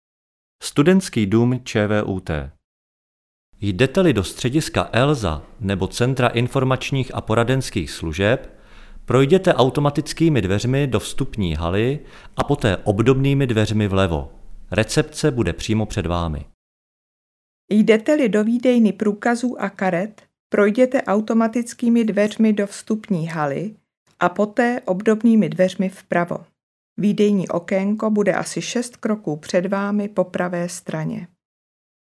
Orientační hlasový majáček Orientační hlasový majáček
Na osu vstupních dveří je osazen orientační hlasový majáček.